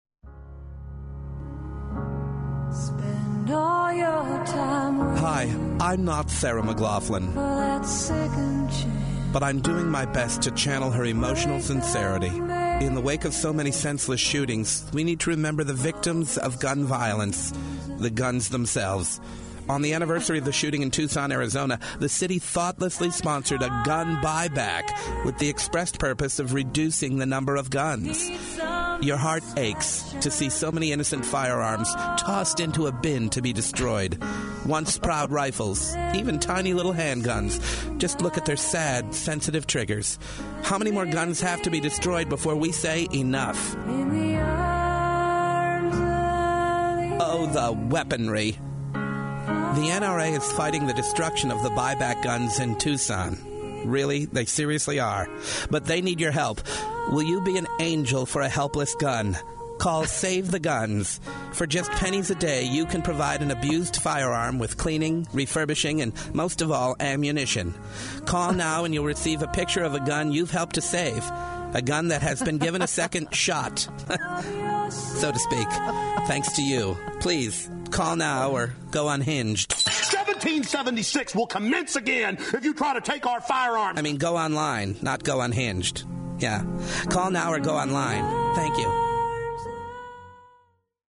Here is a spoof I heard played on the Randi Rhodes show Thursday. It features Sarah McLachlan’s song “In the Arms of an Angel” in the background while a man pleads to “save the guns.”